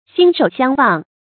心手相忘 注音： ㄒㄧㄣ ㄕㄡˇ ㄒㄧㄤˋ ㄨㄤˋ 讀音讀法： 意思解釋： 極言得心應手。